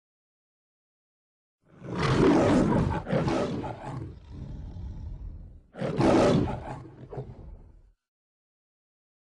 Lion-Roar-Sound-Effect.mp3